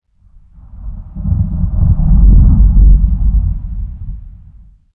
thunder22.ogg